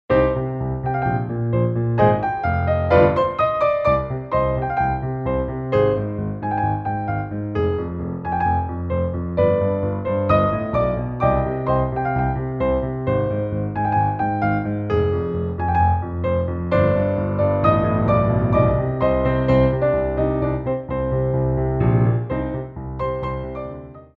Coda
2/4 (8x8)